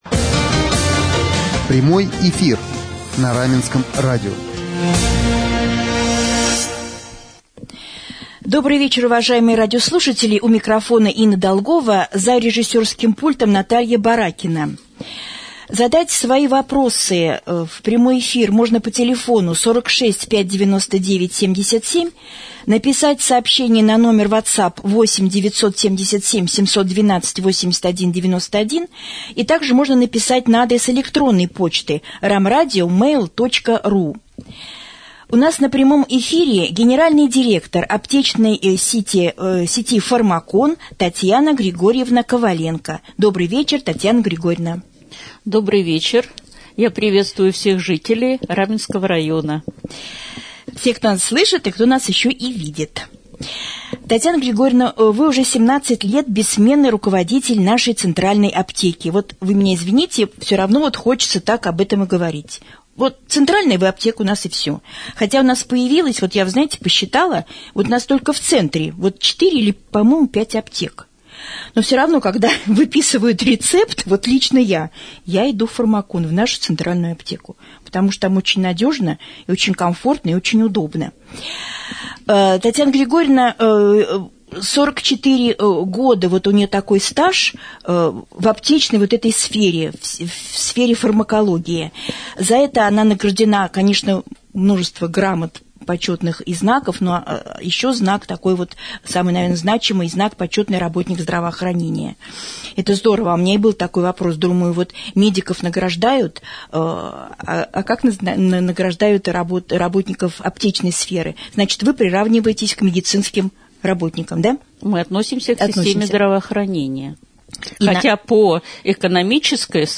гостем вечернего эфира на Раменского радио